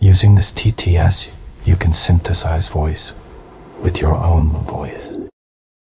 Coqui TTSを用いたボイスクローニング
もちろん滑らかさや自然さの点では同じ言語にしたほうが良いでしょうけど、そんなに違和感なく他言語で喋らせることができます。
[ 英語合成例 ]